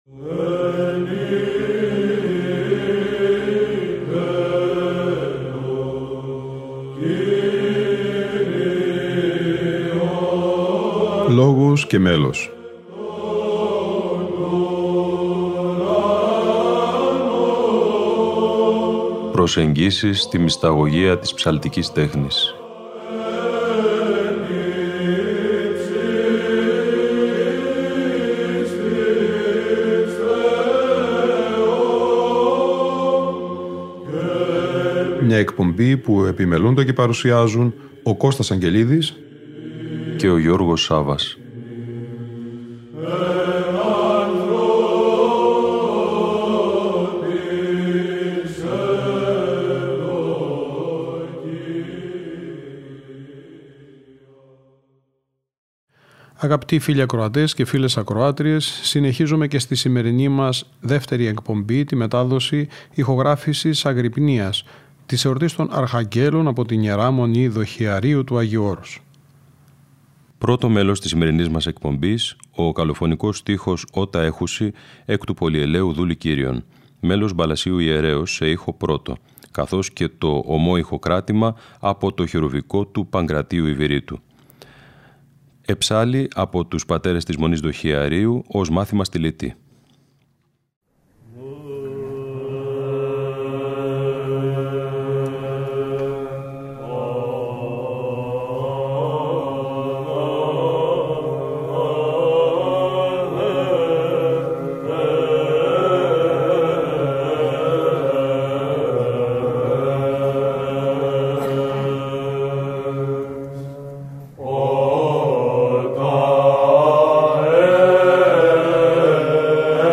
Αγρυπνία Αρχαγγέλων στην Ι.Μ. Δοχειαρίου (Β΄)